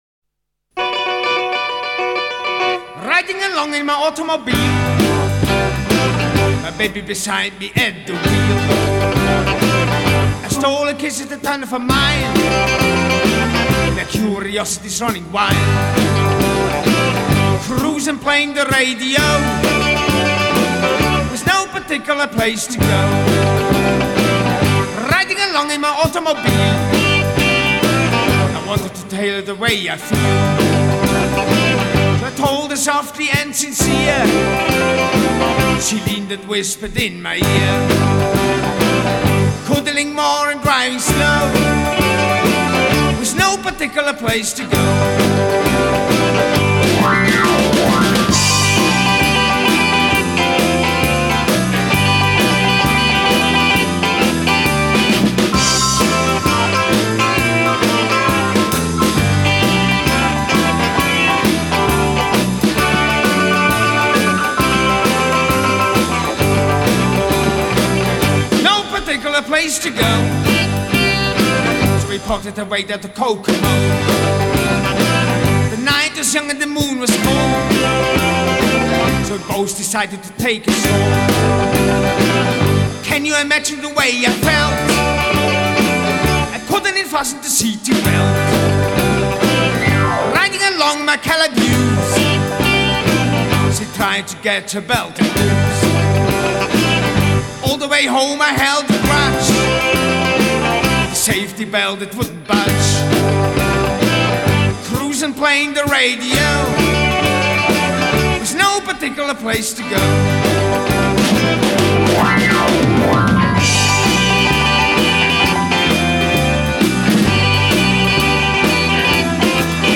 Category: Rock'n'Roll
Bass
Guitar, Mouthharmonica, Blues Harp, Vocals
Piano, Organ
Solo-Guitar, Vocals
Drums on